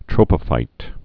(trōpə-fīt, trŏpə-)